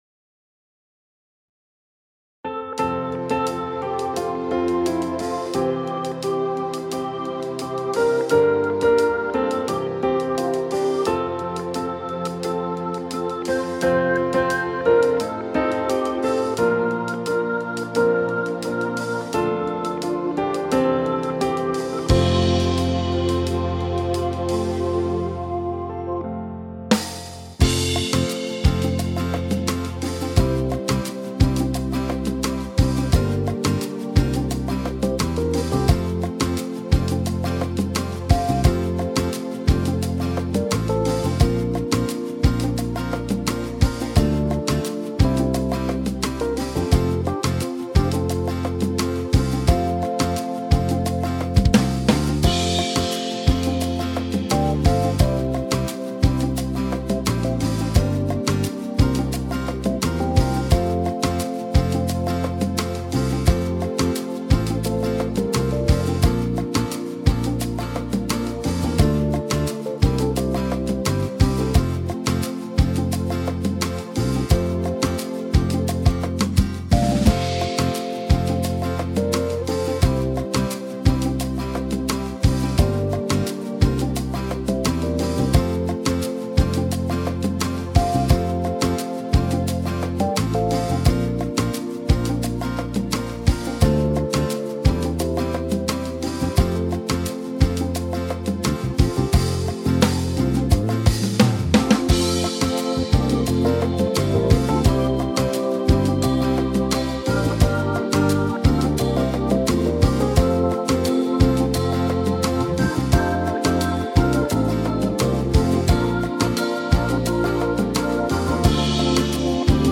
Cantiques (Siège de Porto-Novo)